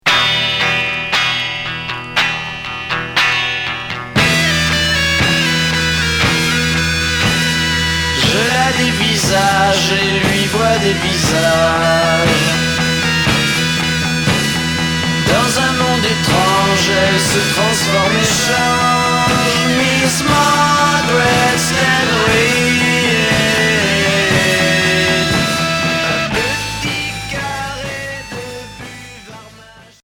Garage psychédélique